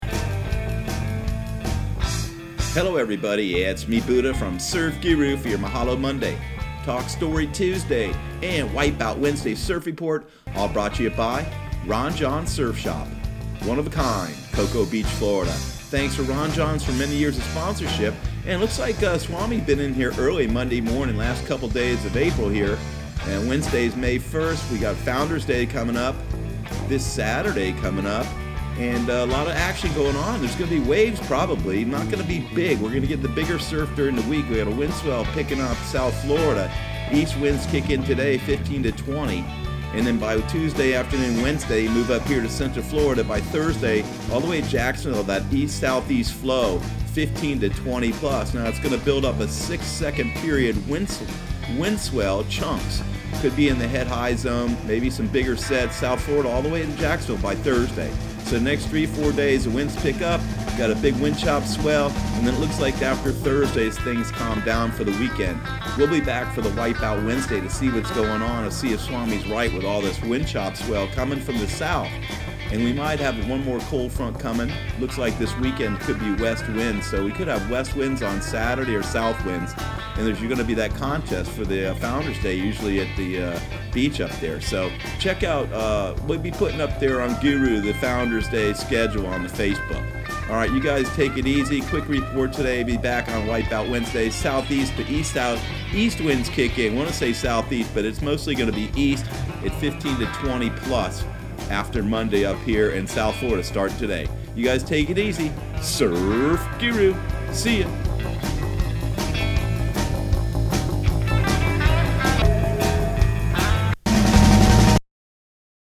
Surf Guru Surf Report and Forecast 04/29/2019 Audio surf report and surf forecast on April 29 for Central Florida and the Southeast.